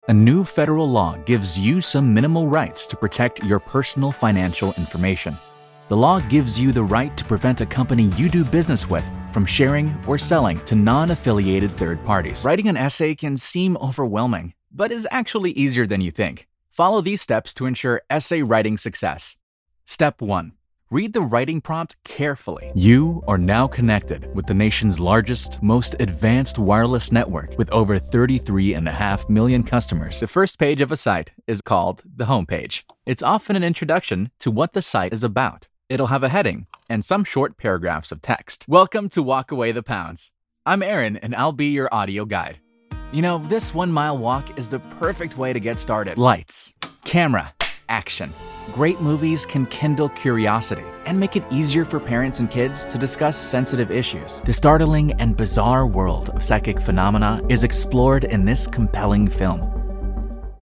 Mature Adult, Teenager, Adult, Young Adult Has Own Studio
Location: Orlando, FL, USA Languages: english 123 english 123 english 123 Voice Filters: VOICEOVER GENRE commercial commercial commercial e-learning e-learning e-learning